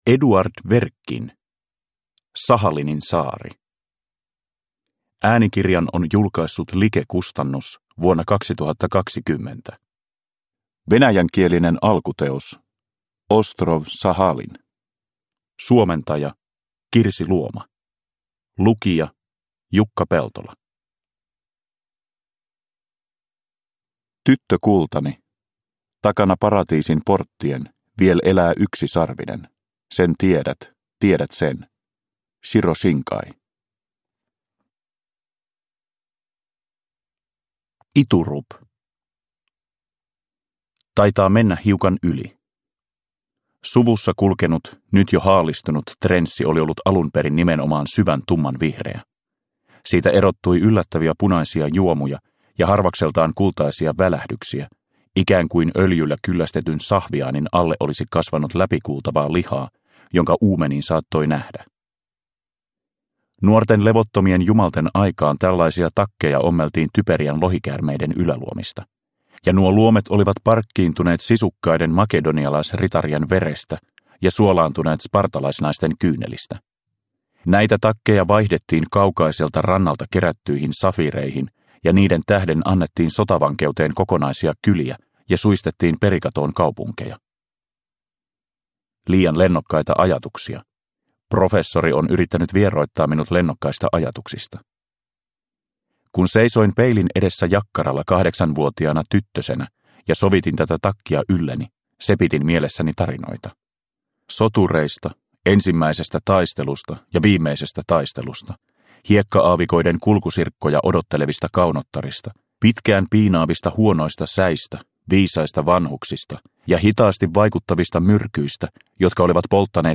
Sahalinin saari – Ljudbok – Laddas ner